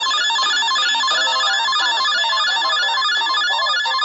sirene_ApSxAFk.mp3